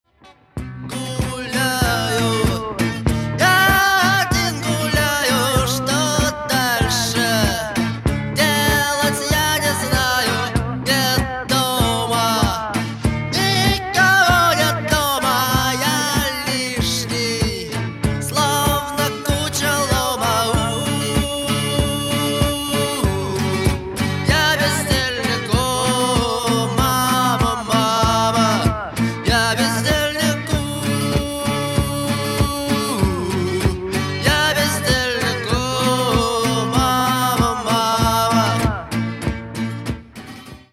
• Качество: 192, Stereo
русский рок